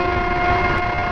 fuelpump.wav